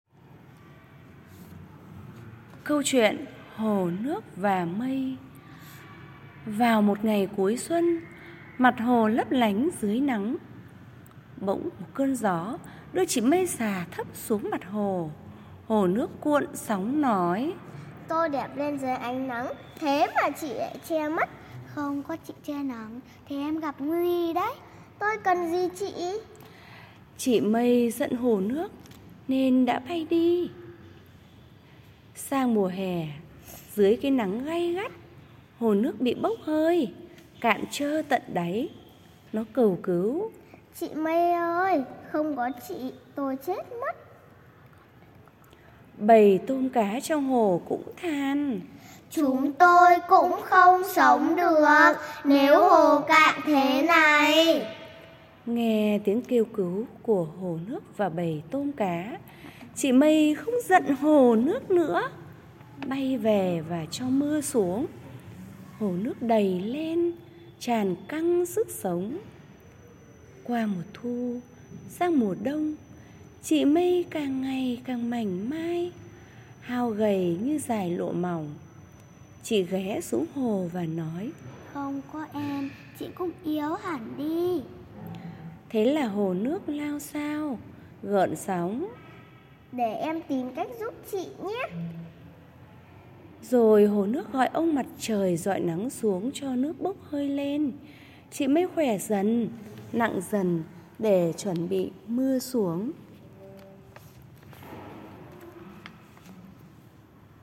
Sách nói | HỒ NƯỚC VÀ MÂY - KỂ CHUYỆN 2